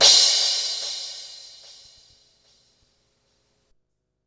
DRO PERK  (1).wav